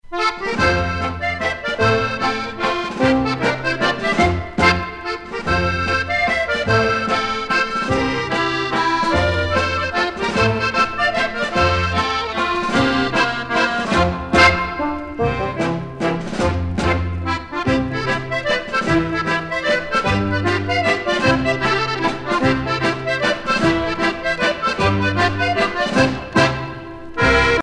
danse : mazurka-java
Pièce musicale éditée